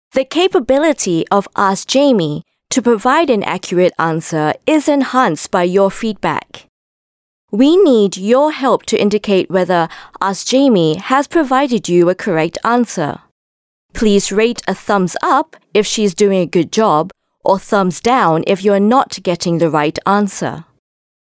Voice Samples: MOF (VO Only)
EN Asian
female